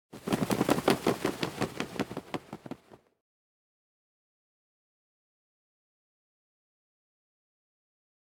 flutter.ogg